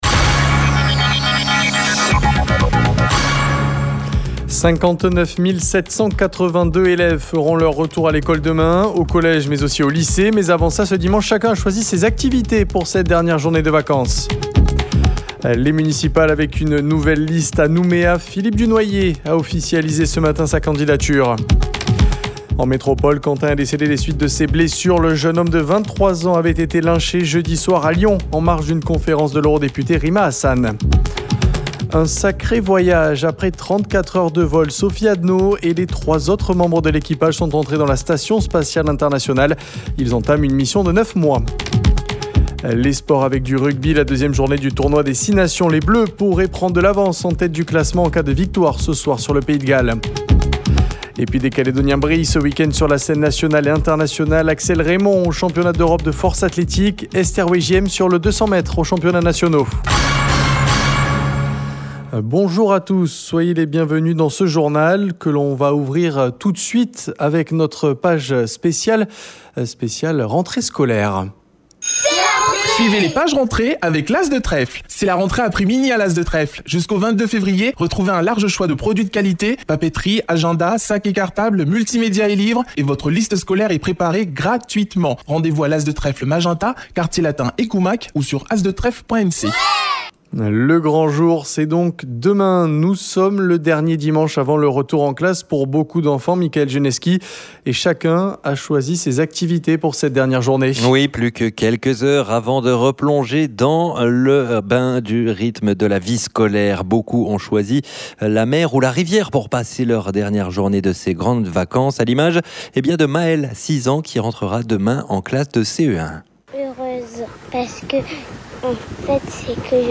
Vous l’entendrez dans notre journal, à midi. Demain, près de 60 000 élèves retrouveront les bancs des écoles, collèges et lycées…